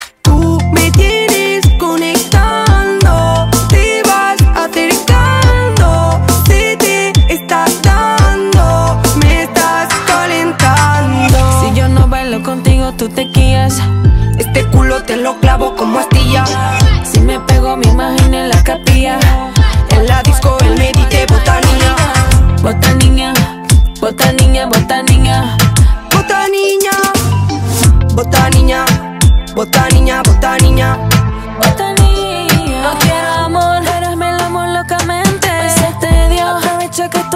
Categoría Rap